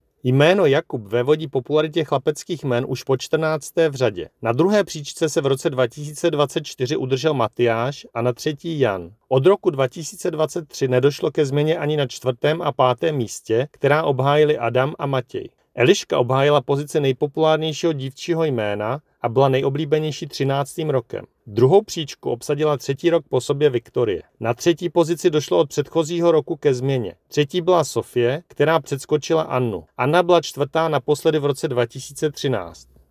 Vyjádření Marka Rojíčka, předsedy ČSÚ, k populárním jménům, soubor ve formátu MP3, 1.27 MB